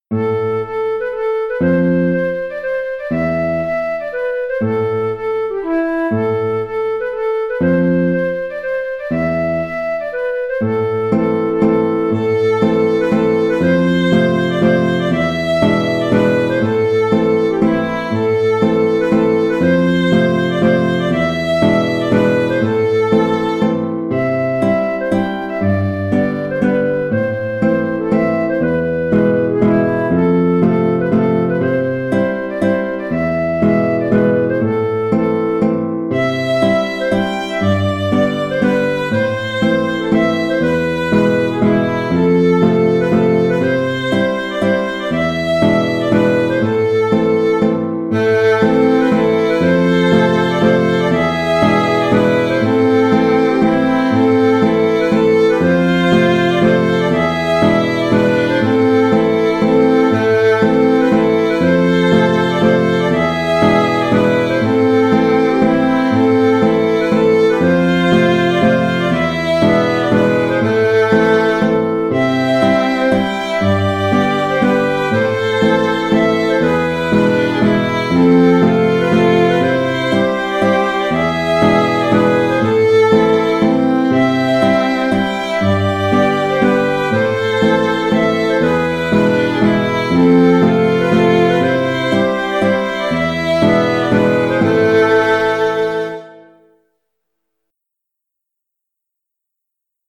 Vent d'Automne (en Am) (Mazurka) - Musique folk